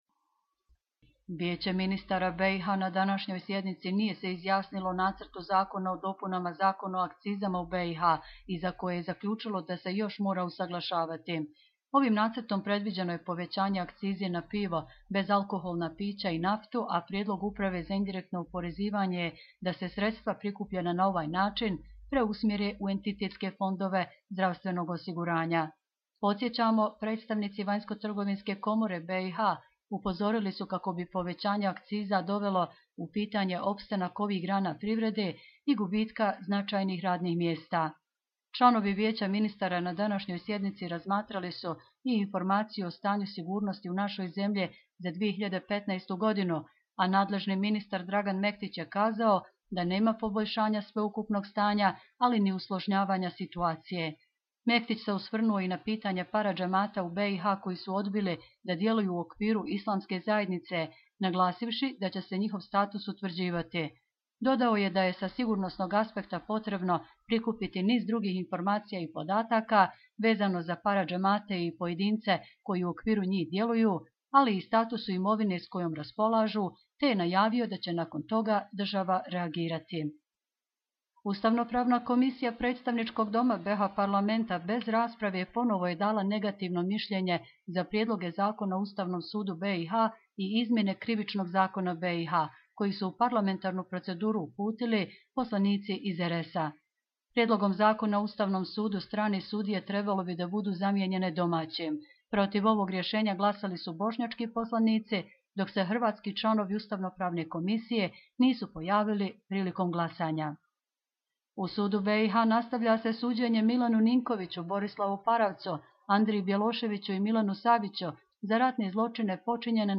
Audio izvještaji